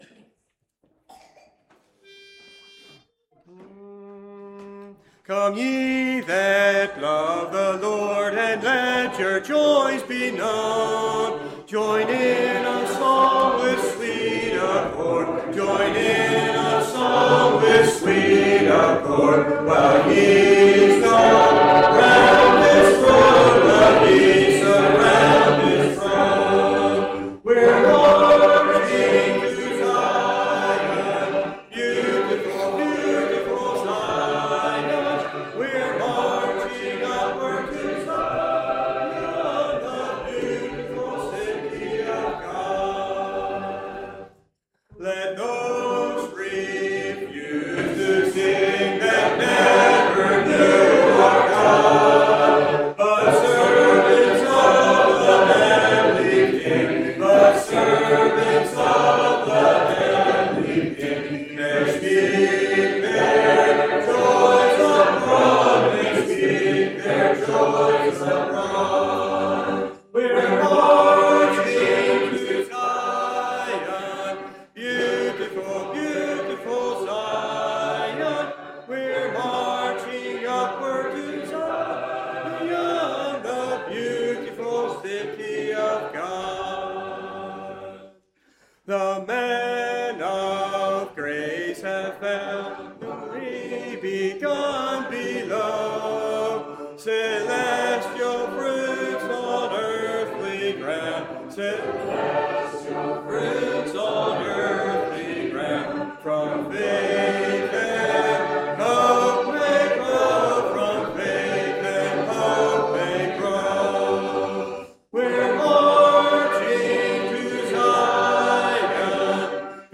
2026 Hymn Sing